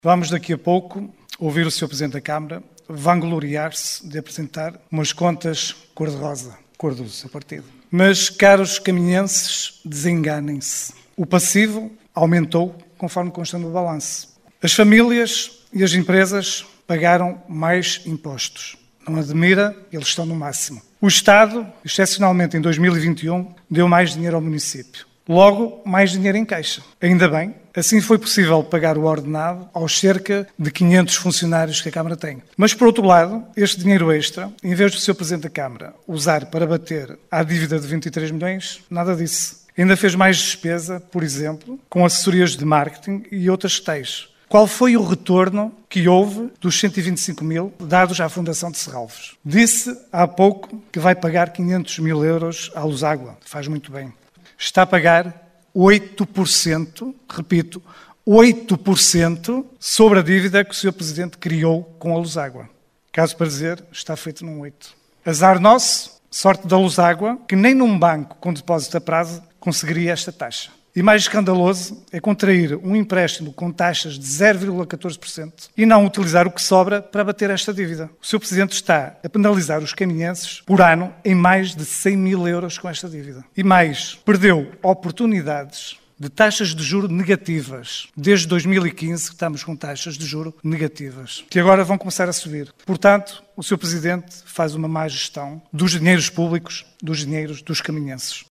O relatório de contas relativo ao ano de 2021 foi aprovado por maioria na última Assembleia Municipal de Caminha.
Luis Fernandes, Coligação “O Concelho em Primeiro”